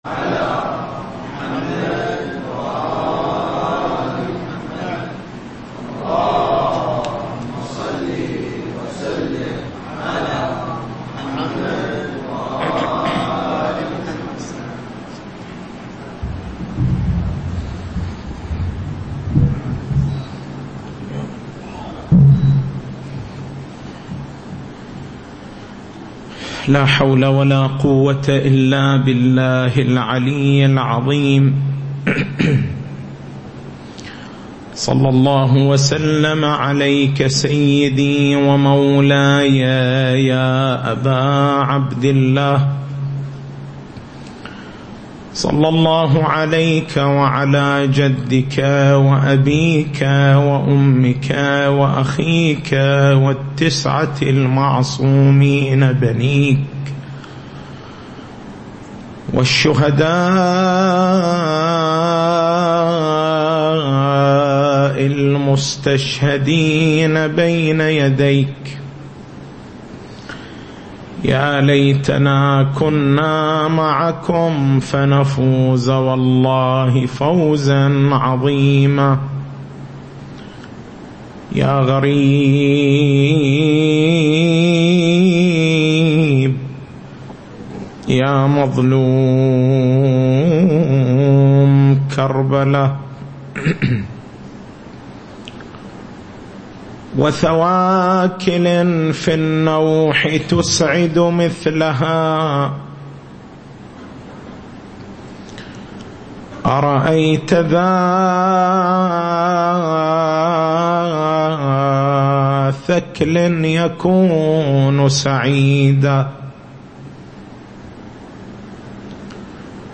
تاريخ المحاضرة: 04/09/1439 نقاط البحث: تعقيب حول مسألة خطأ التركيز على الترهيب في العملية التربوية النقطة الأولى: معنى الأسماء من السمة بمعنى العلامة من السمو بمعنى الرفعة النقطة الثانية: هل الأسماء اللفظية هي الأسماء أم هي أسماء الأسماء؟